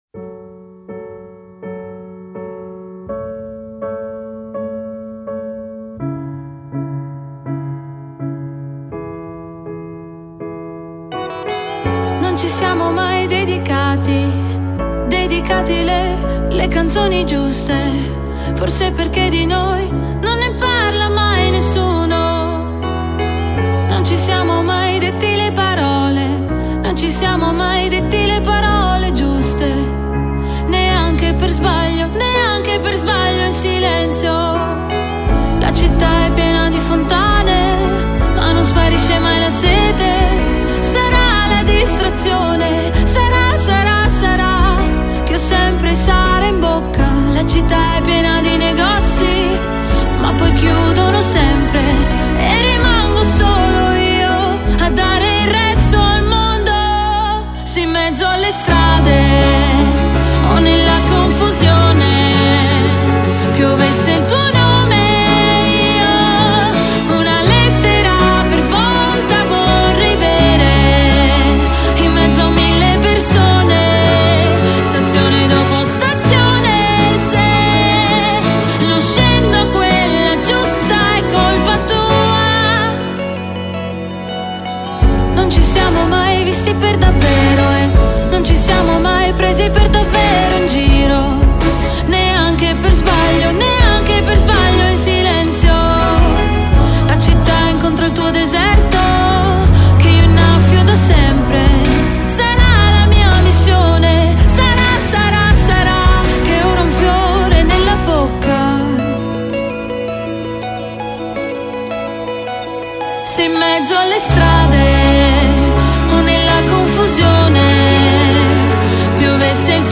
آهنگ ایتالیایی ملایم احساسی عاشقانه با صدای زن